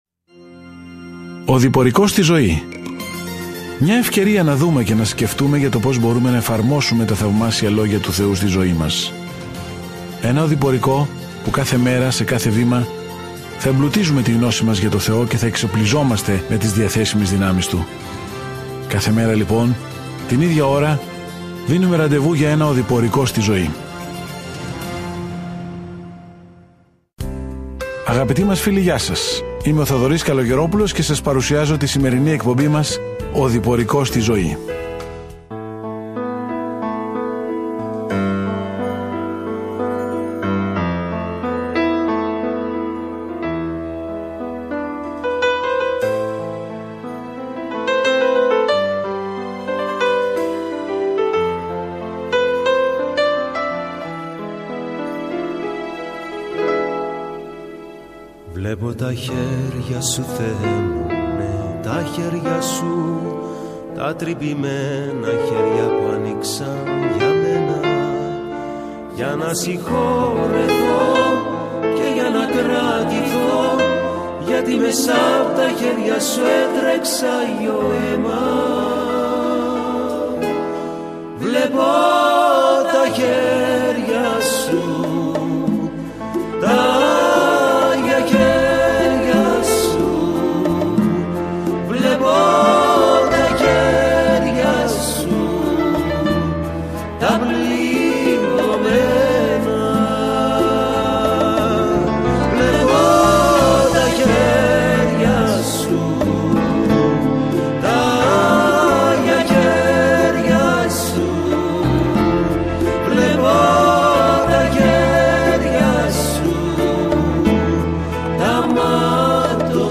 Κείμενο ΜΑΛΑΧΙΑΣ 2:10-17 Ημέρα 8 Έναρξη αυτού του σχεδίου Ημέρα 10 Σχετικά με αυτό το σχέδιο Ο Μαλαχίας υπενθυμίζει σε έναν αποκομμένο Ισραήλ ότι έχει ένα μήνυμα από τον Θεό προτού υπομείνουν μια μακρά σιωπή - η οποία θα τελειώσει όταν ο Ιησούς Χριστός μπει στη σκηνή. Καθημερινά ταξιδεύετε στη Μαλαχία καθώς ακούτε την ηχητική μελέτη και διαβάζετε επιλεγμένους στίχους από τον λόγο του Θεού.